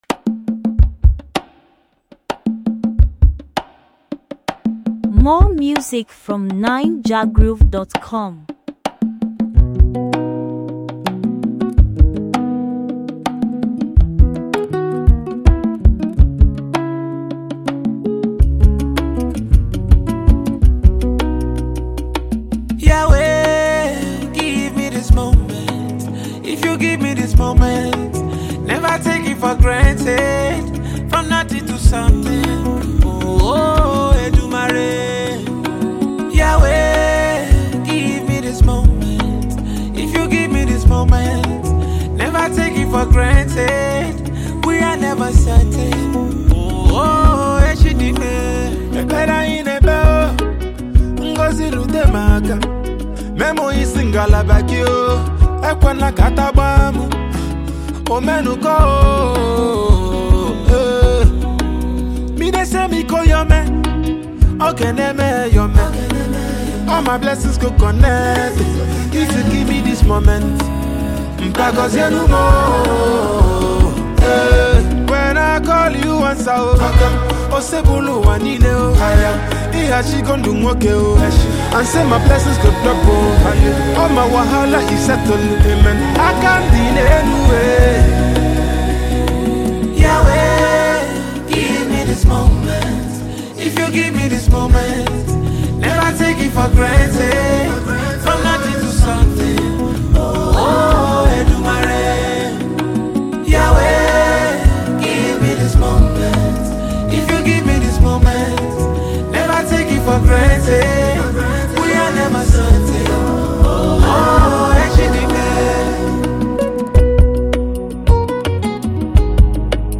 Naija-music